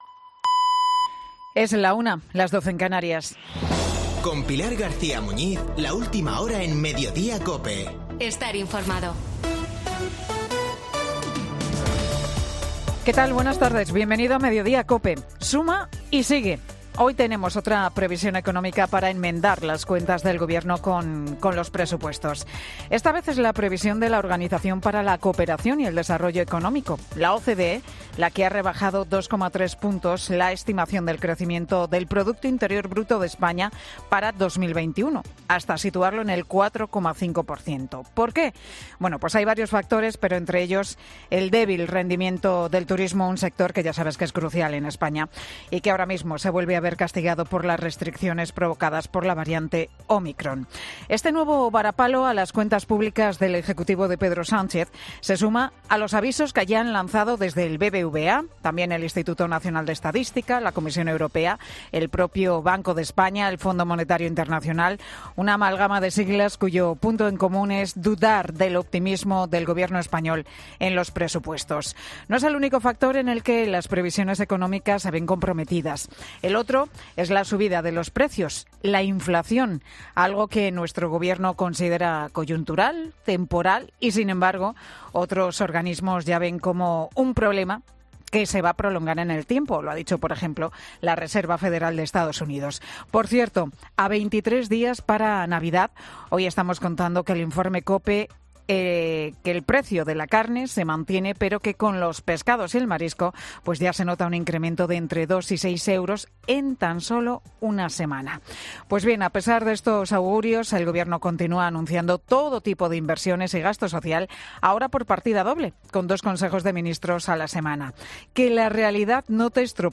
Monólogo de Pilar García Muñiz
El monólogo de Pilar G. Muñiz en Mediodía COPE